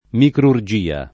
micrurgia [ mikrur J& a ] s. f.